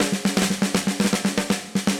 Index of /musicradar/80s-heat-samples/120bpm
AM_MiliSnareB_120-02.wav